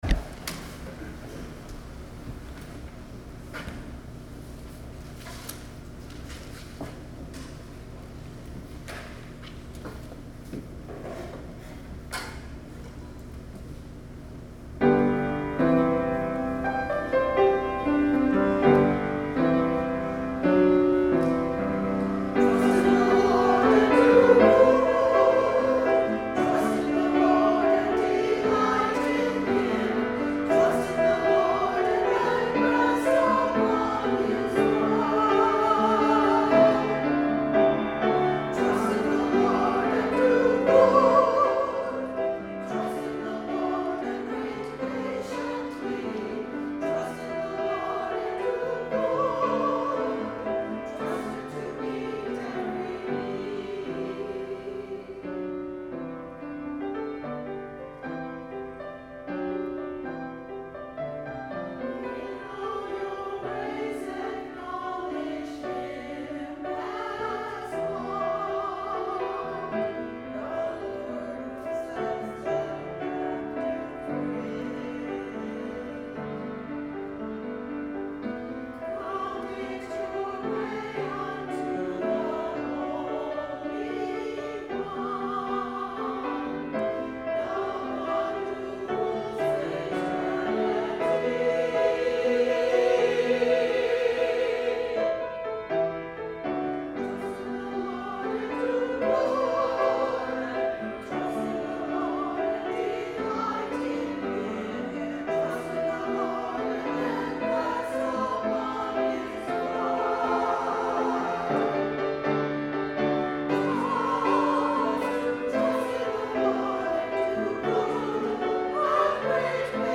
Music from September 29, 2019 Sunday Service
Choir Anthem